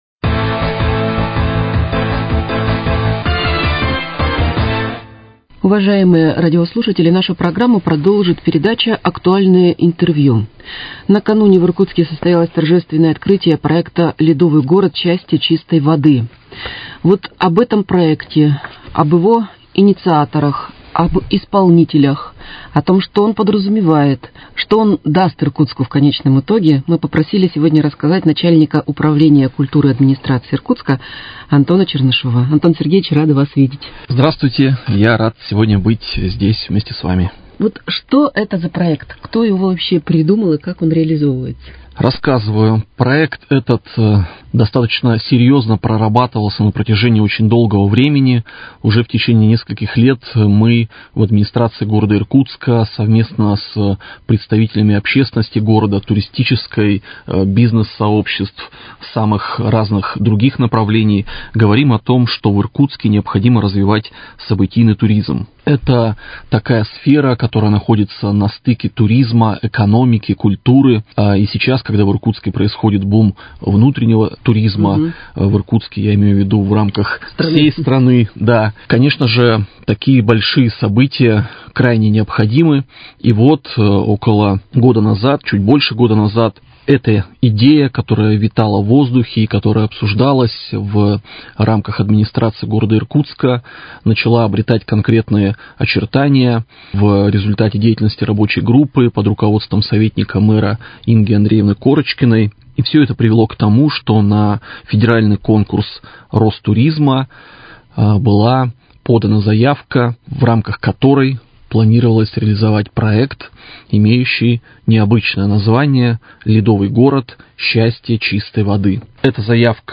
Актуальное интервью: О старте проекта Ледовый город «Счастье чистой воды»